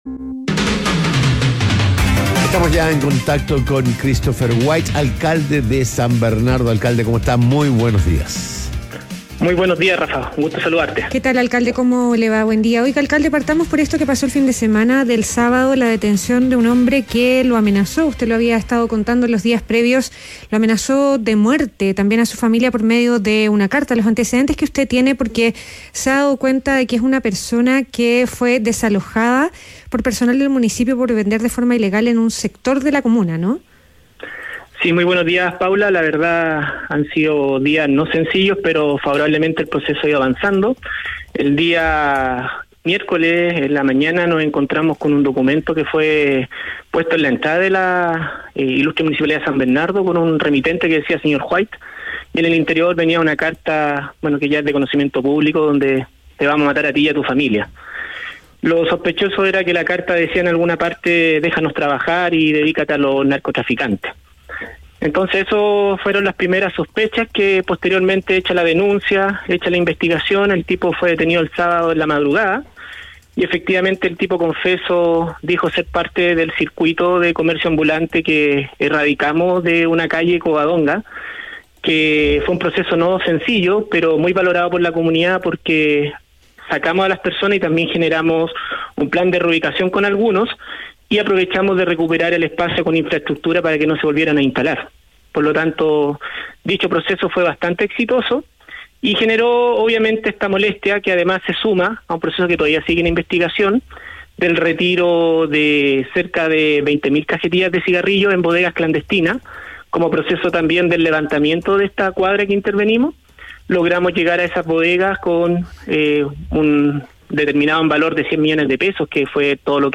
ADN Hoy - Entrevista a Christopher White, alcalde de San Bernardo